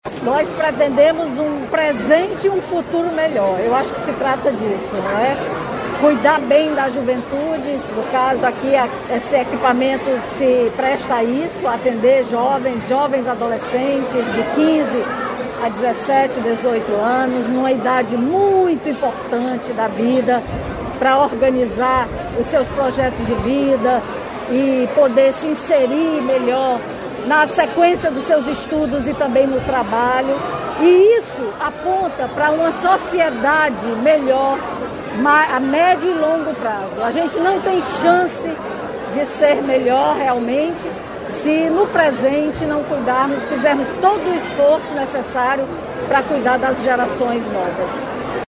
Nova sede da Escola de Ensino Médio Deputado Manoel Rodrigues é inaugurada em Fortaleza
Conhecida por ter dado o pontapé na revolução que a educação cearense passou, em meados dos anos 2000, a vice-governadora Izolda Cela destacou o compromisso em cuidar bem dos estudantes para garantir um futuro melhor para eles.